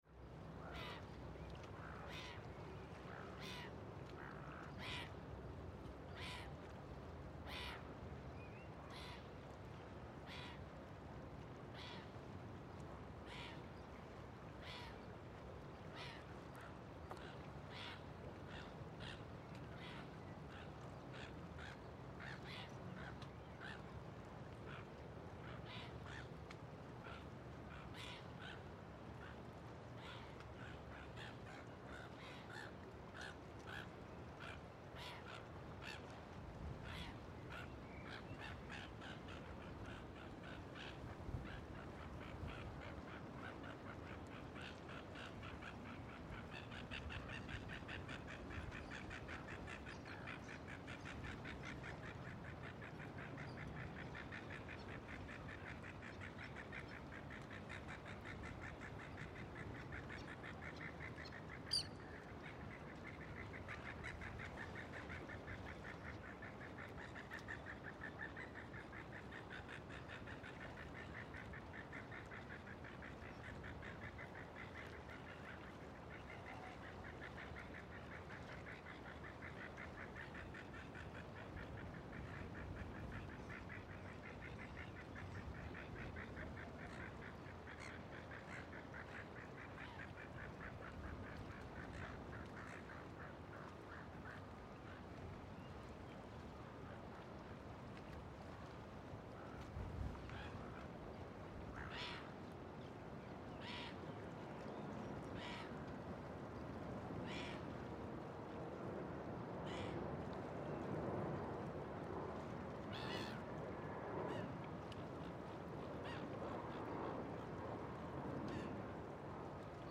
Звуки порта в Веллингтоне
• Категория: Атмосферные звуки (интершум) Новой Зеландии
• Качество: Высокое